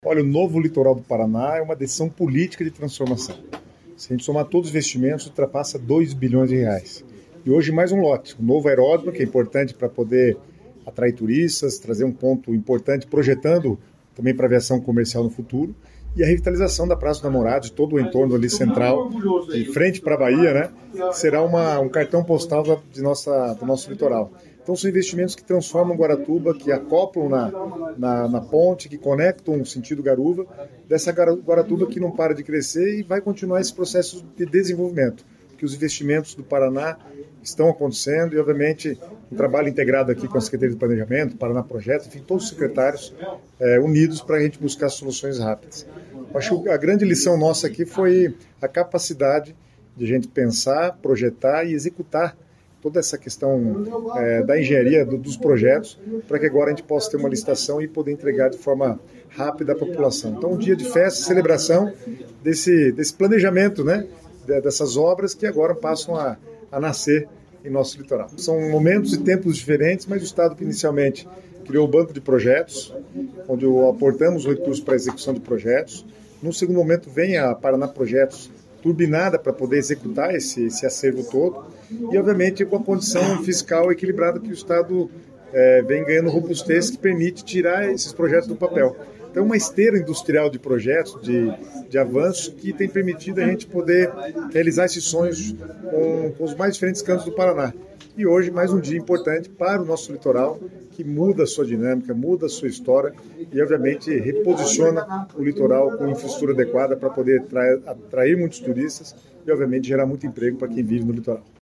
Sonora do secretário das Cidades, Guto Silva, sobre os novos investimentos em Guaratuba